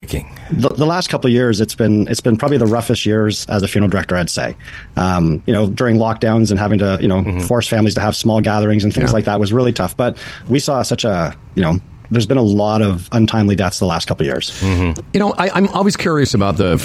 Lots of untimely deaths says this funeral home owner